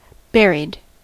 Ääntäminen
Synonyymit interred Ääntäminen US : IPA : [ˈbɛ.ɹid] Haettu sana löytyi näillä lähdekielillä: englanti Käännös Adjektiivit 1. haudattu 2. peitetty Buried on sanan bury partisiipin perfekti.